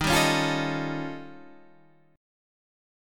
Eb7#11 chord